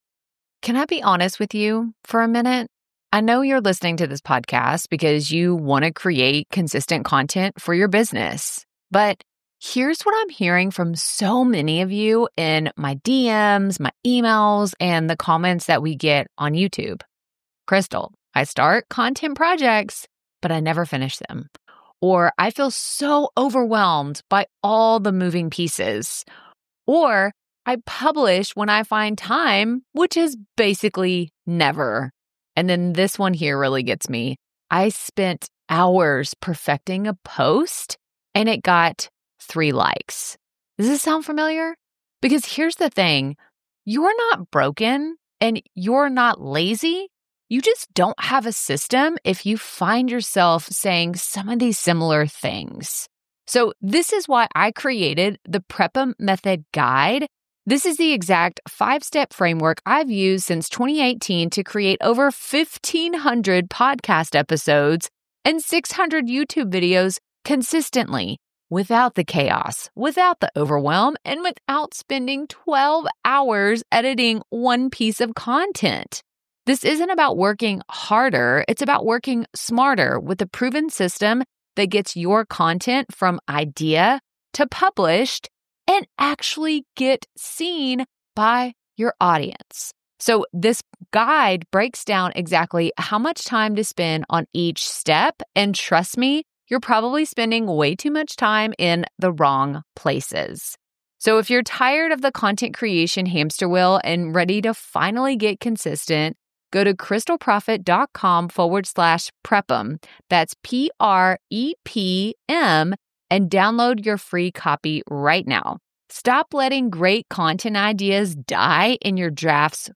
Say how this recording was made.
Building Consistent Creators Through Community, a Message from Kit Studios